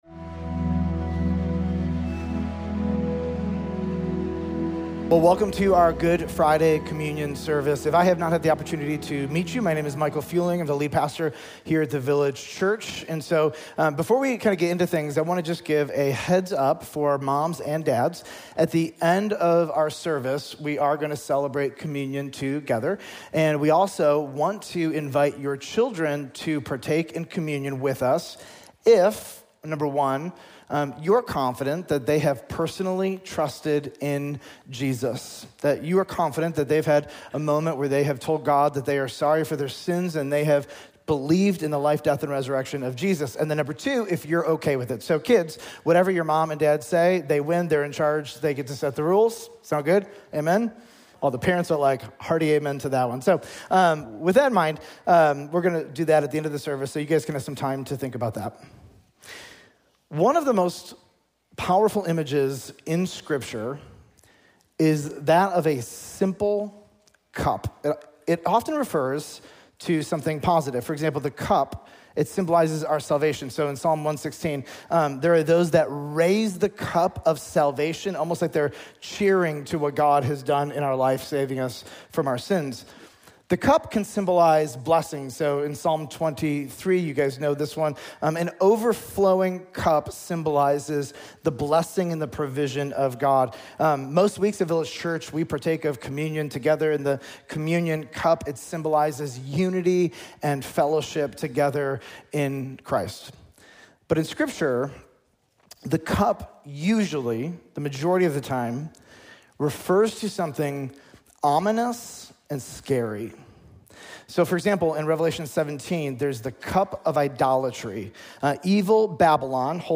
Easter Sunday 2025
Sermons Easter Sunday 2025